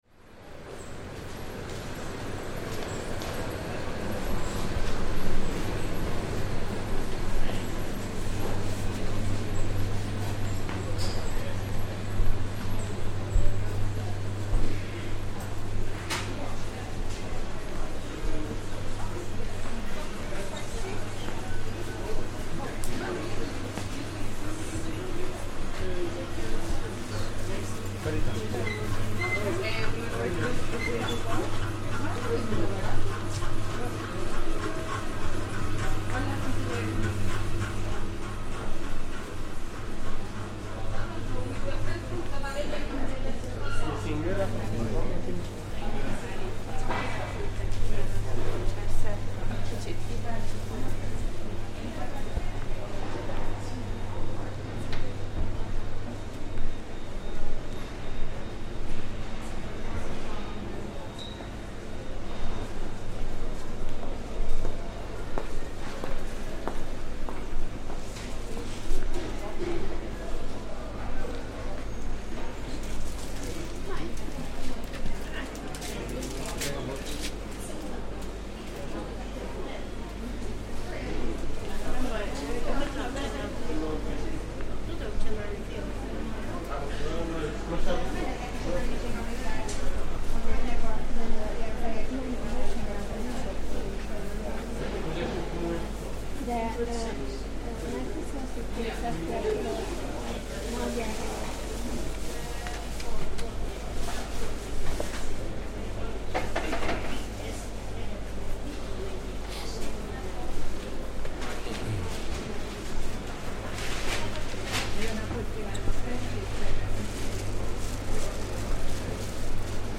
A walkthrough of the famous Central Market Hall in central Budapest, with various market traders and customers doing their morning shopping.